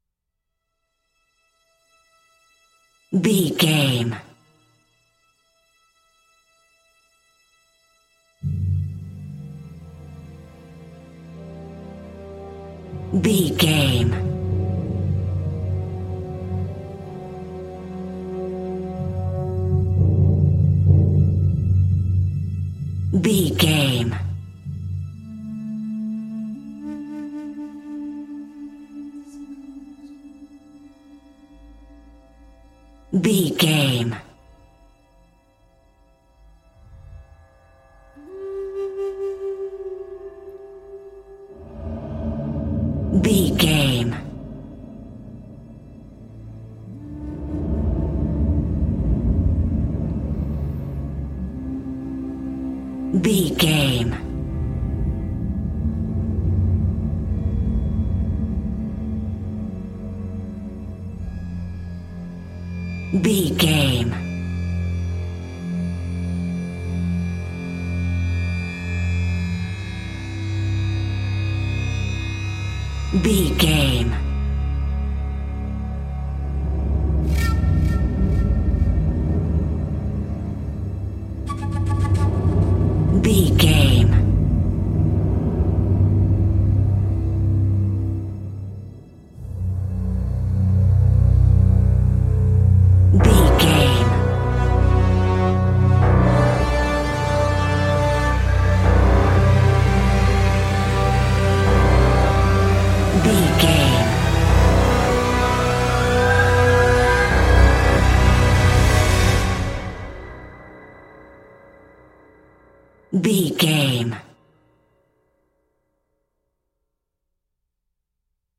In-crescendo
Thriller
Ionian/Major
Slow
eerie
haunting
ominous
tension
strings
percussion
brass
ambient
cinematic